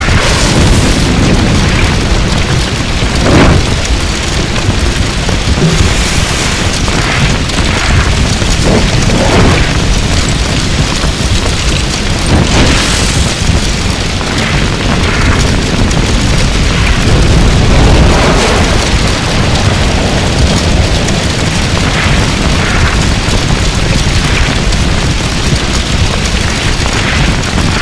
fire_metal_lrg1v2.mp3